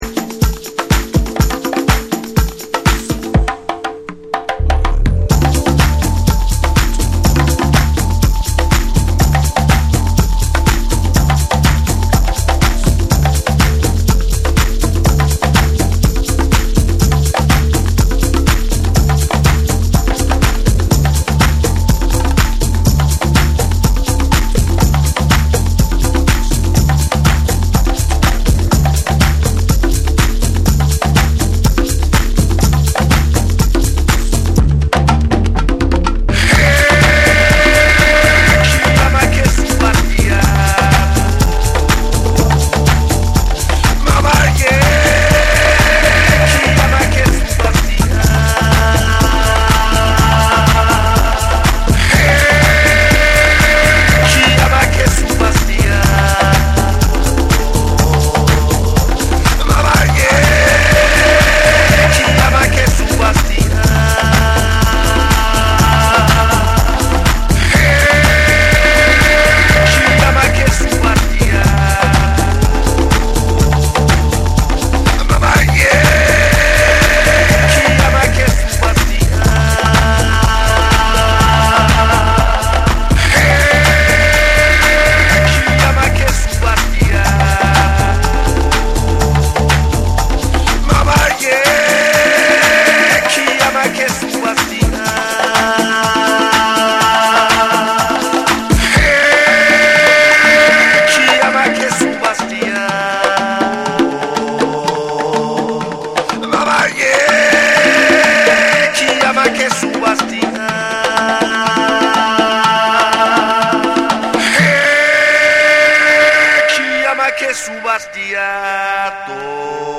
パーカッシッヴなトライバル・ビートに、アフリカンなヴォーカルがフロアを熱くするオーガニック・ハウス
TECHNO & HOUSE / ORGANIC GROOVE